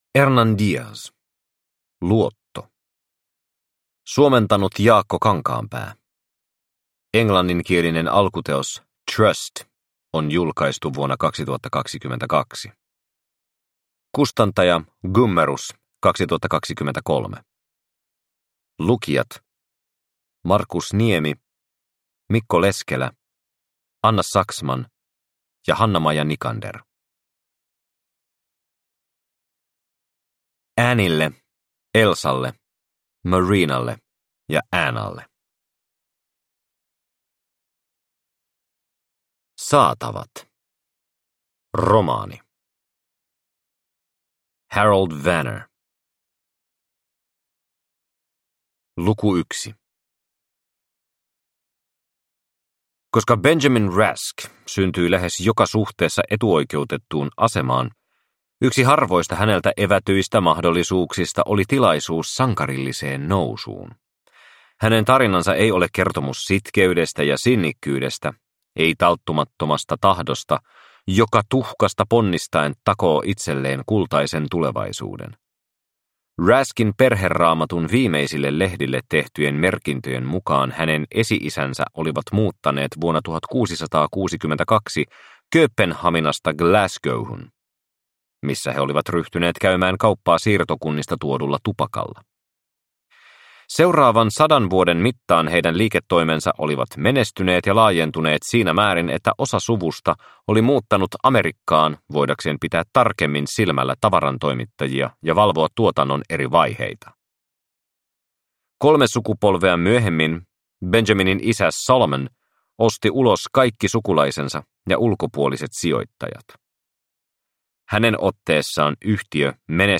Luotto – Ljudbok – Laddas ner